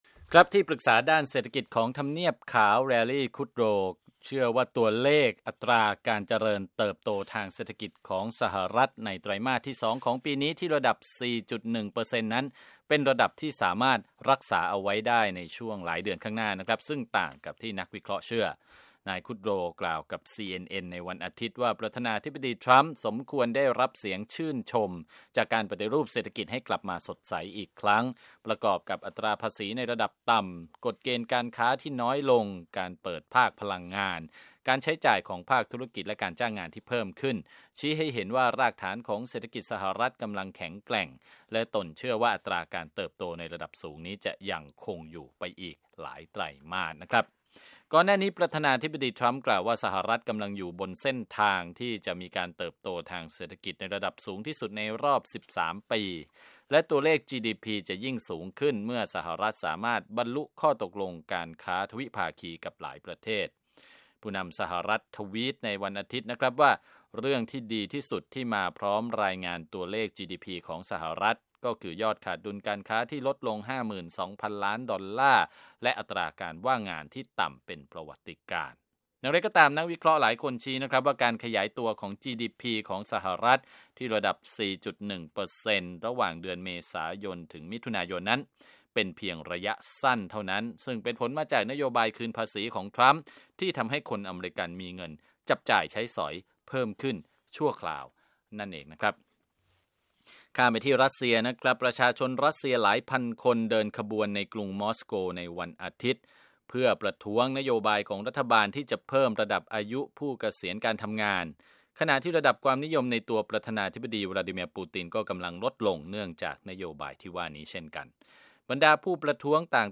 ข่าวธุรกิจ 29 ก.ค. 2561